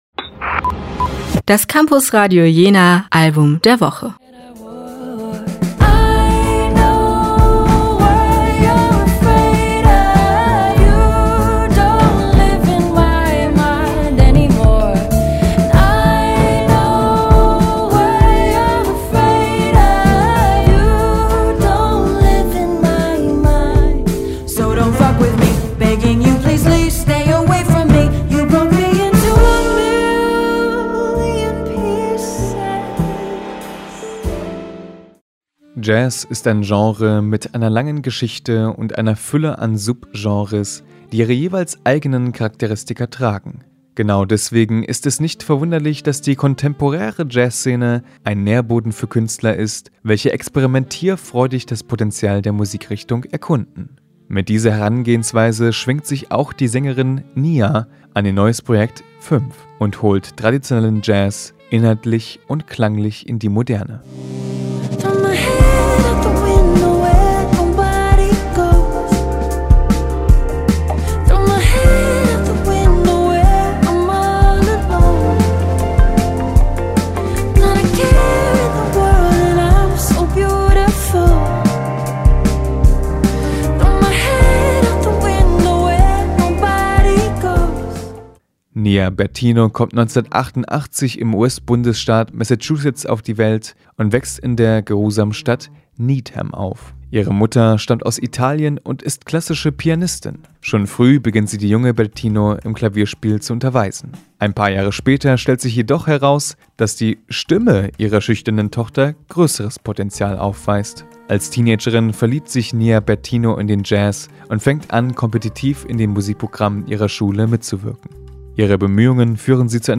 Jazz ist ein Genre mit einer langen Geschichte und einer Fülle an Subgenres, die ihre jeweils eigenen Charakteristika tragen.
Zurückgezogene Covers von Jazz-Klassikern gefolgt von Mixturen aus Akustikinstrumenten und elektronischen Beats machen “V” zu einem Album, wo Tradition auf Moderne trifft.